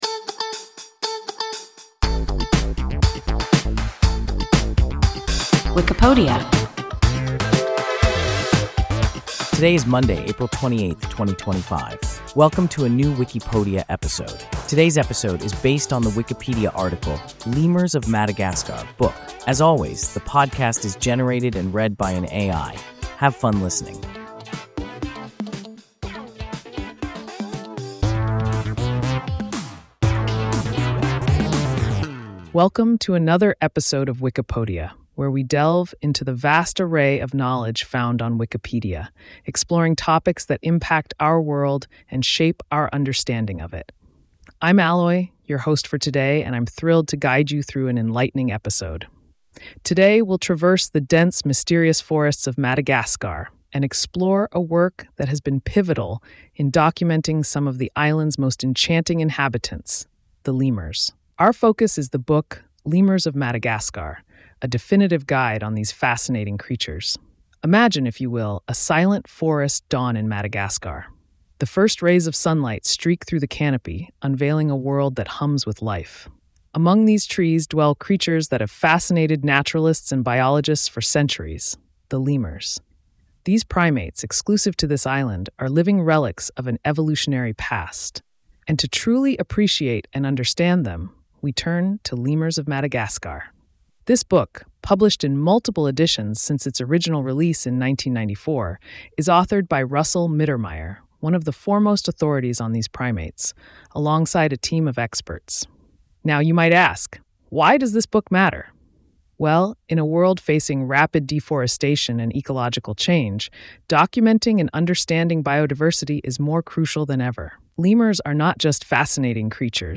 Lemurs of Madagascar (book) – WIKIPODIA – ein KI Podcast
Wikipodia – an AI podcast